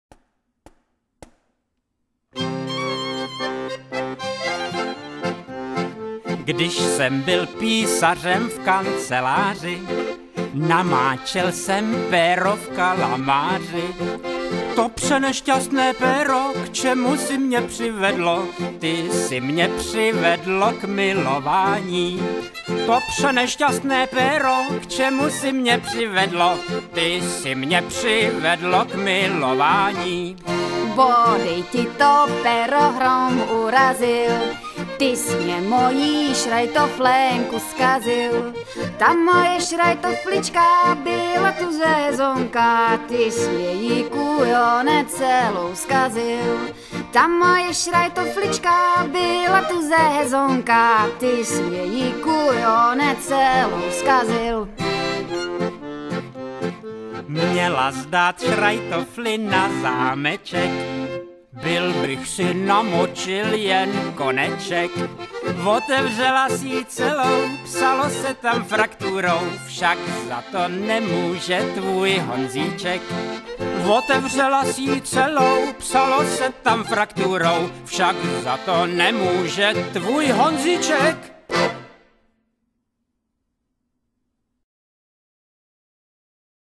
hrají a zpívají
Lidová / Lidová